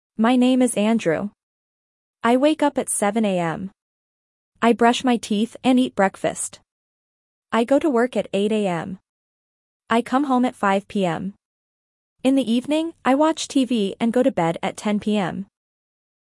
Dictation A1 - My Morning Routine
1.-A1-Dictation-My-Morning-Routine.mp3